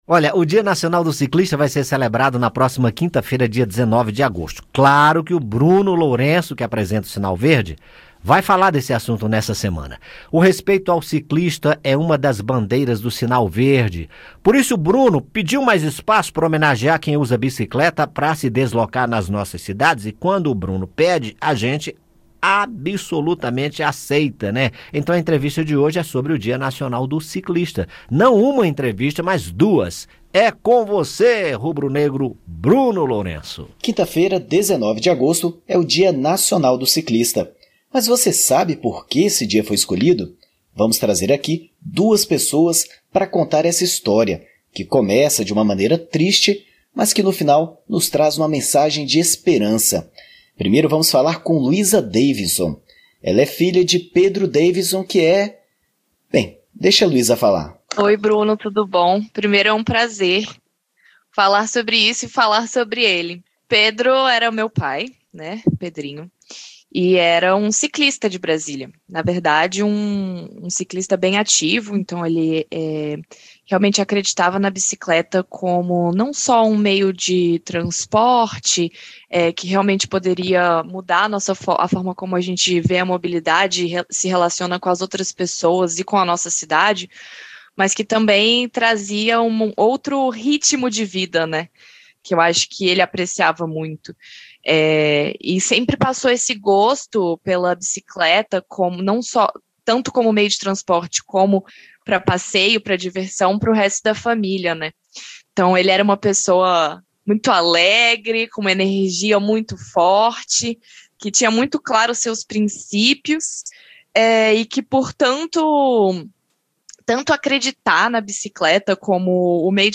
Entrevista: Dia Nacional do Ciclista